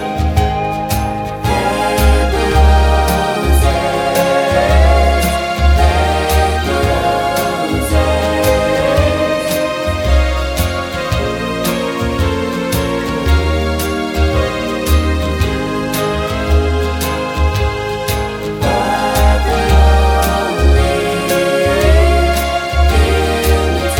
Professional Pop (1970s) Backing Tracks.